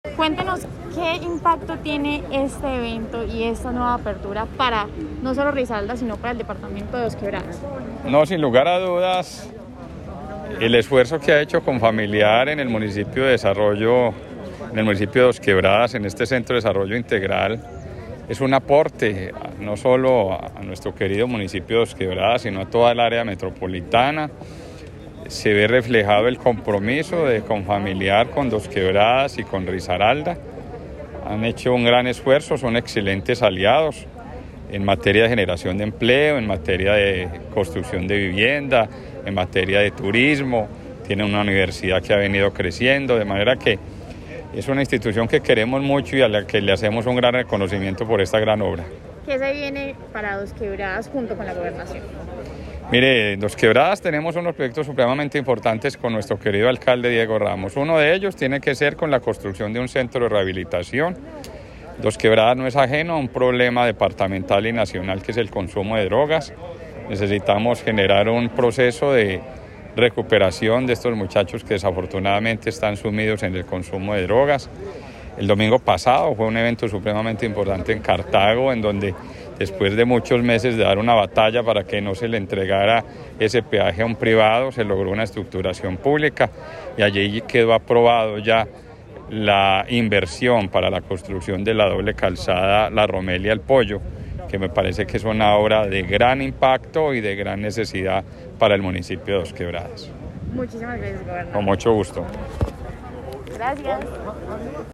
Escuchar Audio: Gobernador de Risaralda, Víctor Manuel Tamayo.
Comunicado-147-Audio-2-Gobernador-de-Risaralda-Victor-Manuel-Tamayo.mp3